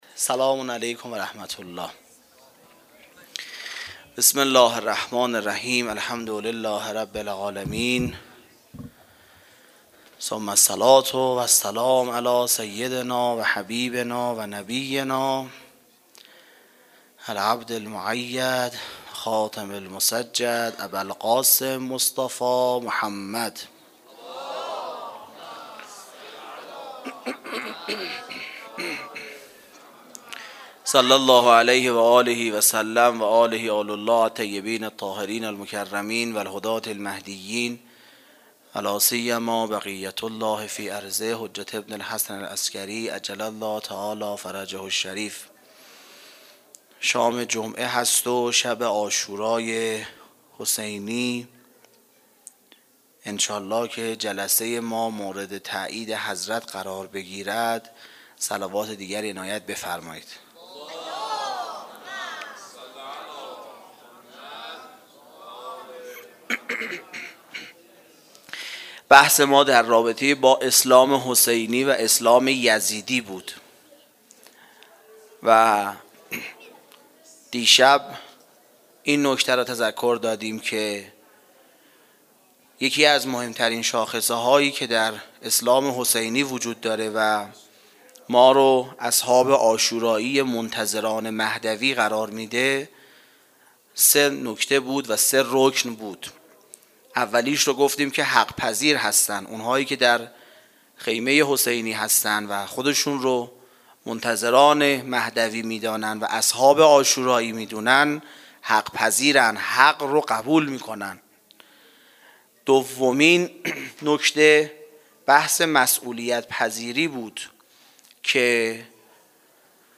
سخنرانی شب دهم محرم
Sokhanrani-Shabe-10-moharram94.mp3